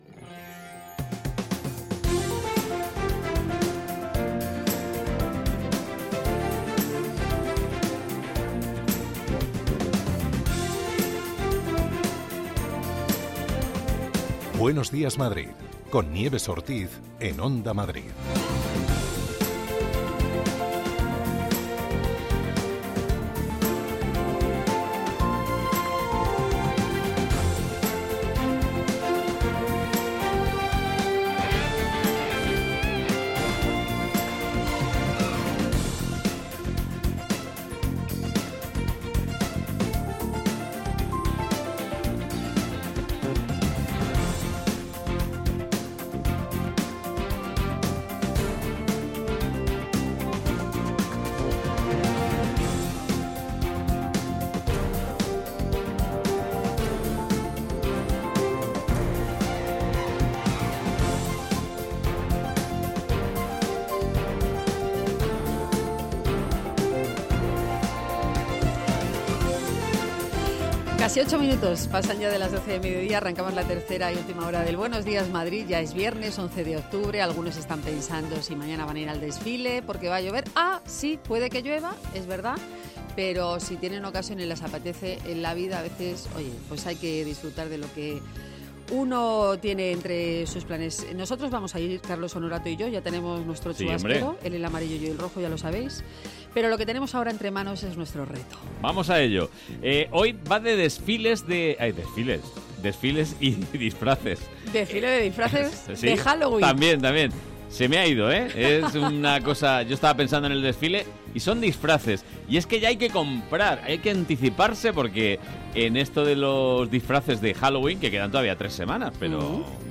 Tres horas más de radio donde se habla de psicología, ciencia, cultura, gastronomía, medio ambiente y consumo.